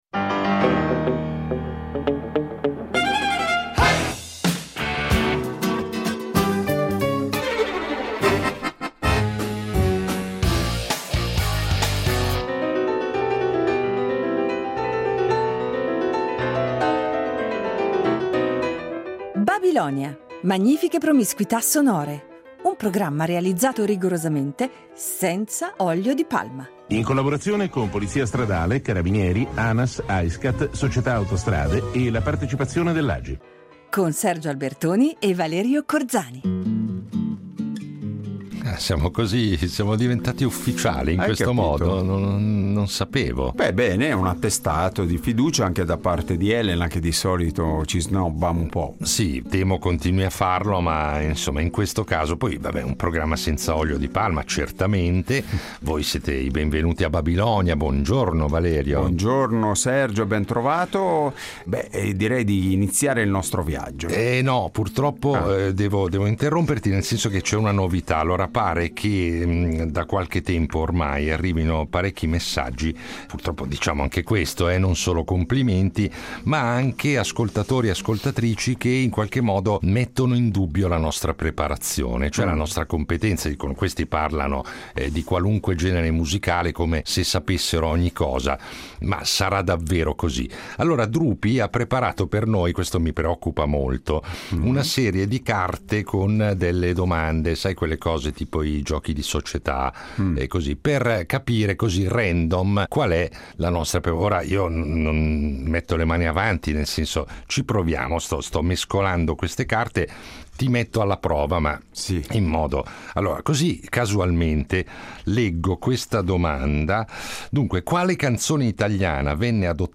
Ecco perché ci sembra doveroso recuperarlo, di tanto in tanto, riunendolo in puntate particolarissime perché svincolate dall’abituale scansione di rubriche e chiacchierate con gli ospiti. Itinerari ancor più sorprendenti, se possibile, che accumulano in modo sfrenato i balzi temporali e di genere che caratterizzano da sempre le scalette di Babilonia .